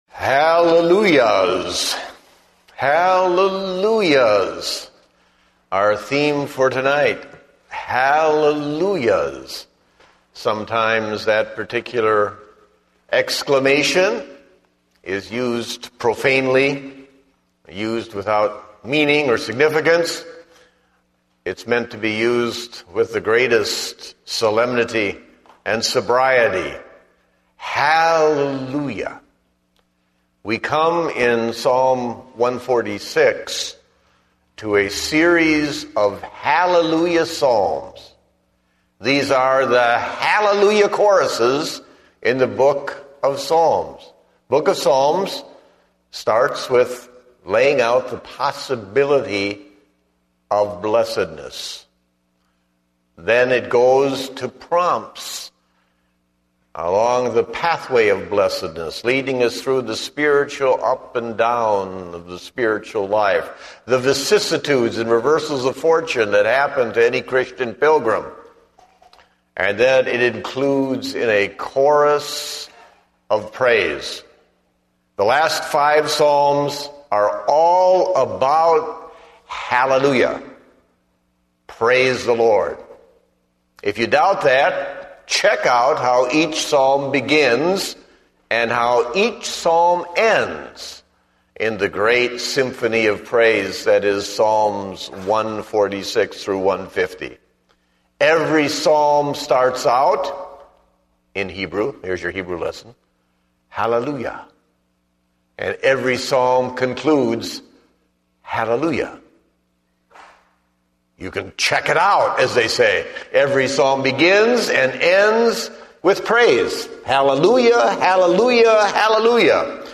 Date: September 5, 2010 (Evening Service)